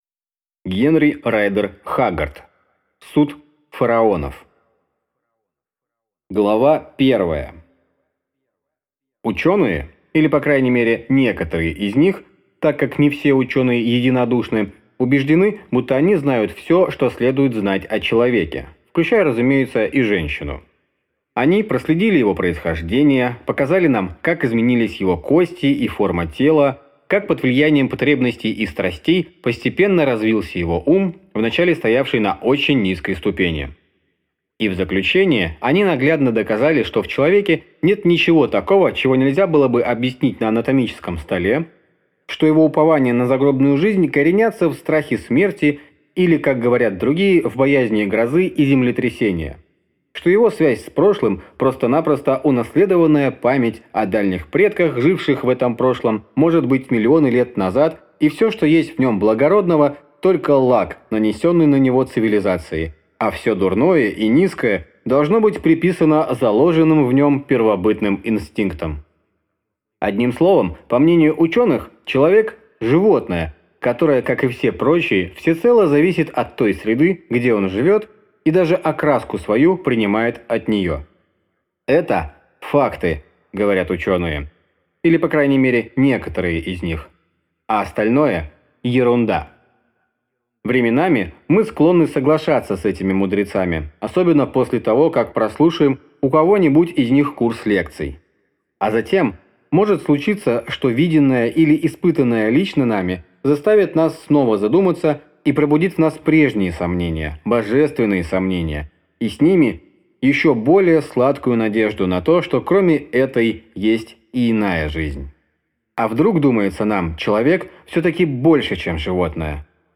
Аудиокнига Суд фараонов | Библиотека аудиокниг